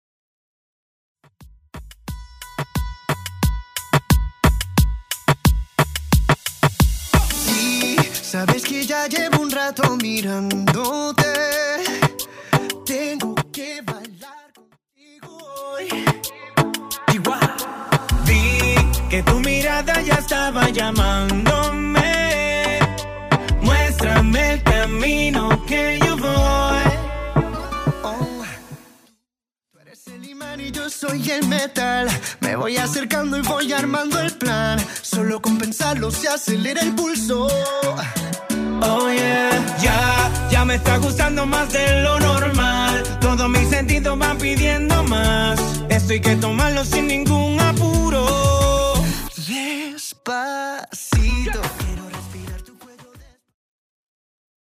Reggaeton